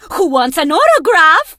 diva_lead_vo_03.ogg